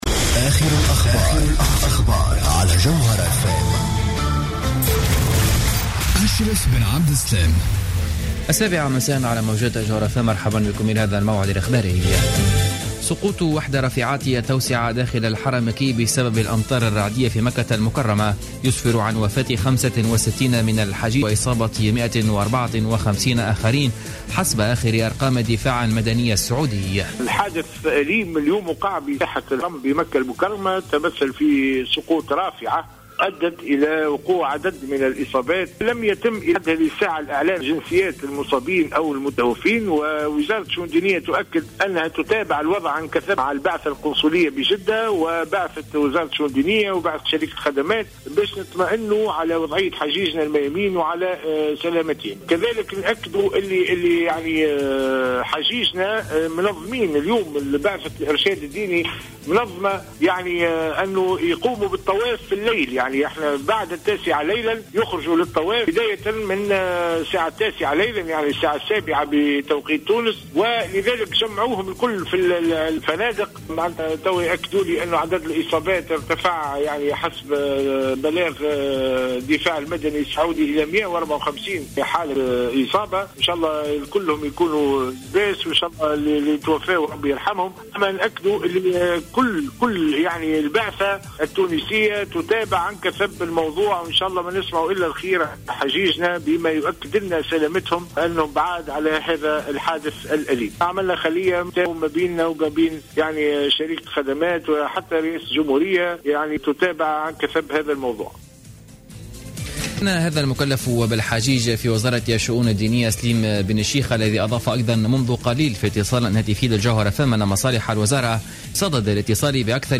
نشرة أخبار السابعة مساء ليوم الجمعة 11 سبتمبر 2015